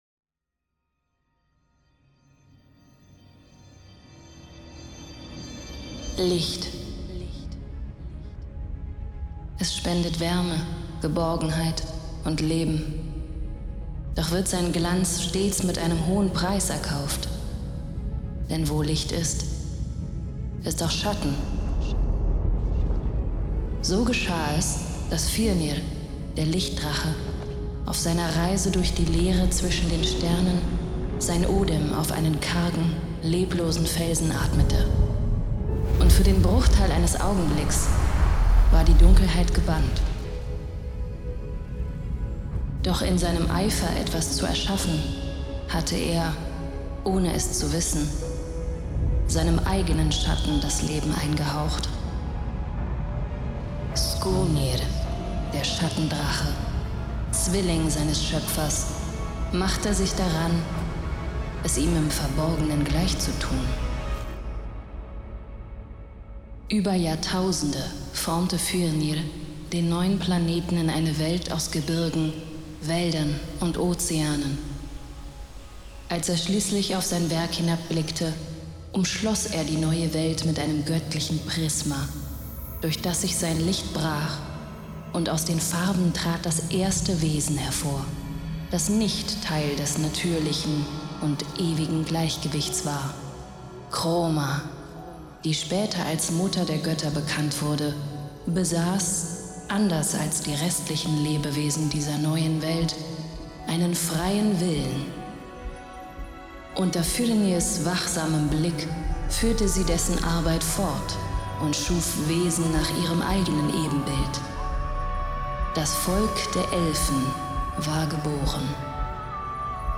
Music courtesy of Riot Games